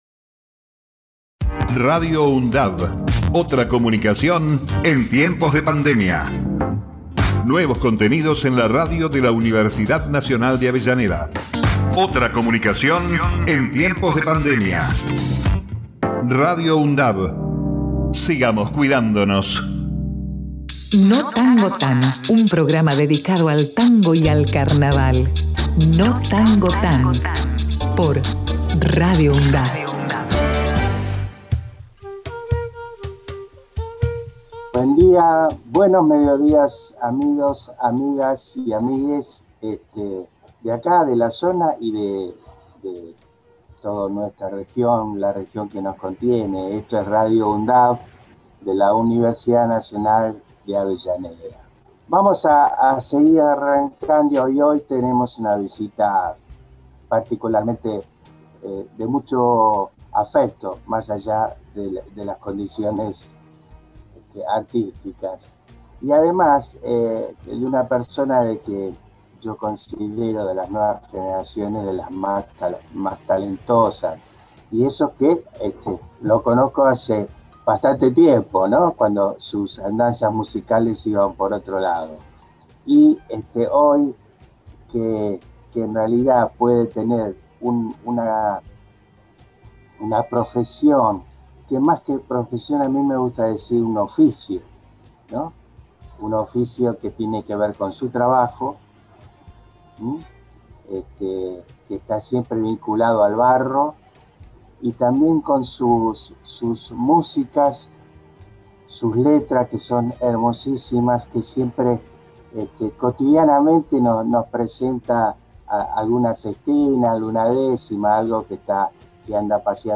Notangotan es un programa temático que aborda la cultura, fundamentalmente musical de Buenos Aires y la región que nos abarca, la Cuenca del Río de la Plata, hacedora del género que nos convoca, el tango y el carnaval. Para conocer la cultura rioplatense y orillera.